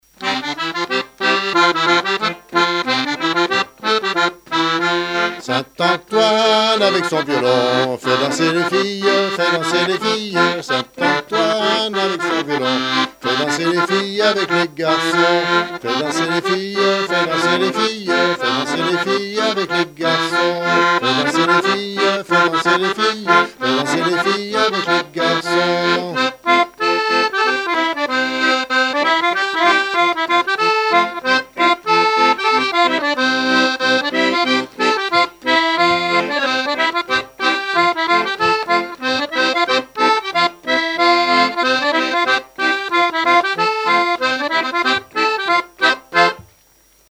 Chants brefs - A danser
scottich trois pas
Pièce musicale inédite